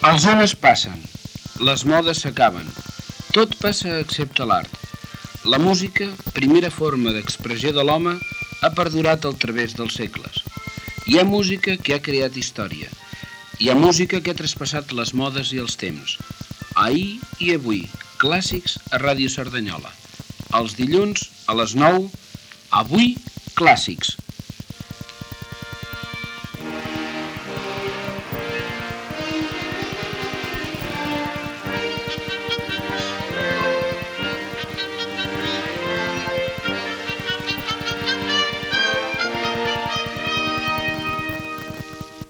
Entrada del programa de música clàssica.